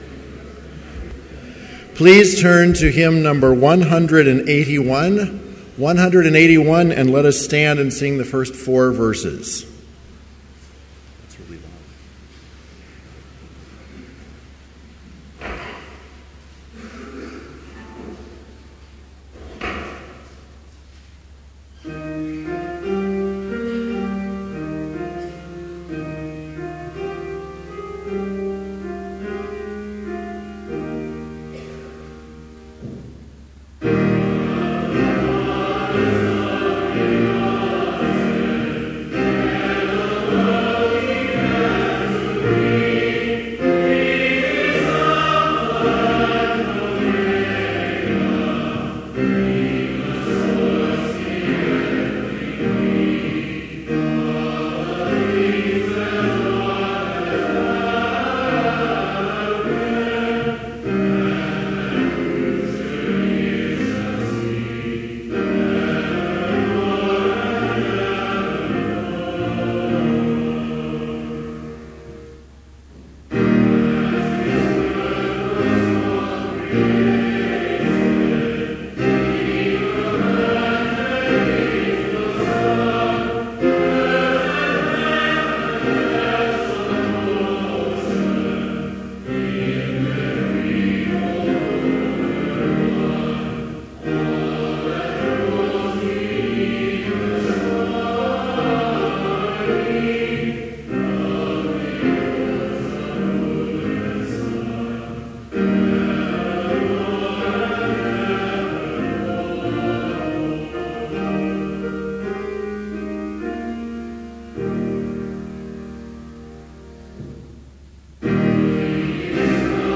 Complete service audio for Chapel - December 17, 2013